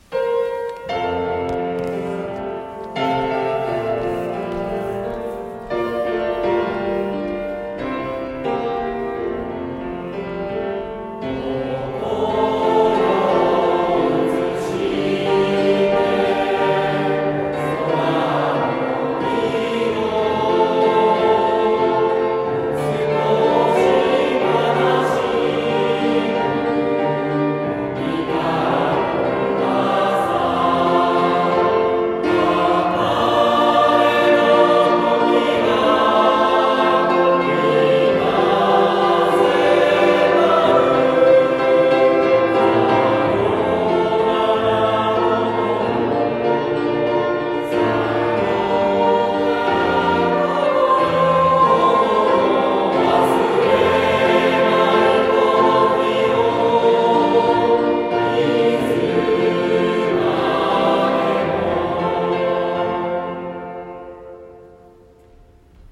６年 卒業式Part5